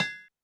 Drums_K4(61).wav